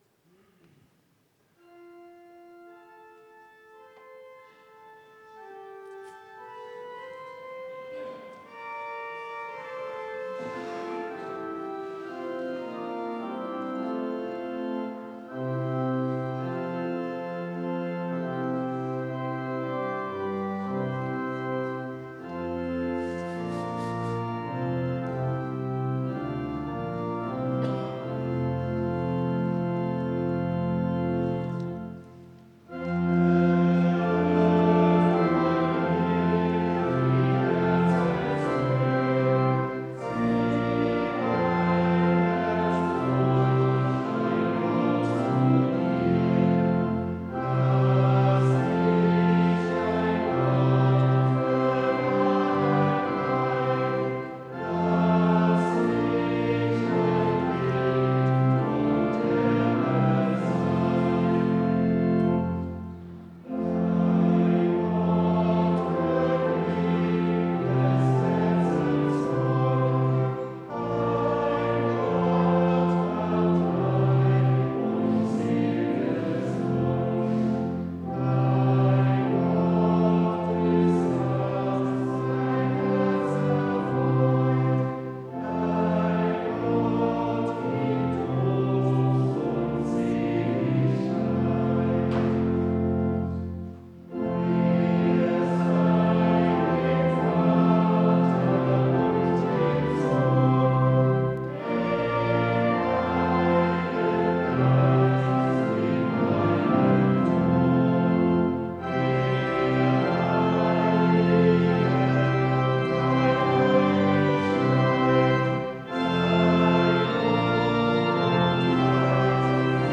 Audiomitschnitt unseres Gottesdienstes am Sonntag Invokavit 2025.